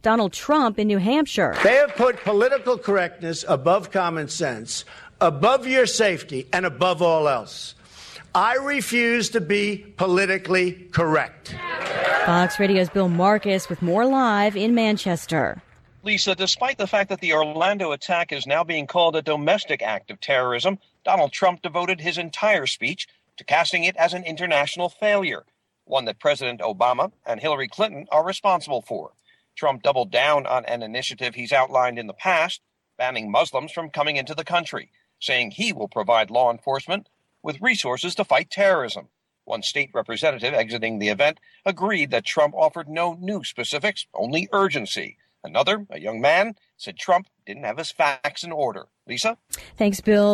FOX NEWS RADIO, LIVE, 4PM –